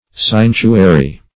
seintuary - definition of seintuary - synonyms, pronunciation, spelling from Free Dictionary Search Result for " seintuary" : The Collaborative International Dictionary of English v.0.48: Seintuary \Sein"tu*a*ry\, n. Sanctuary.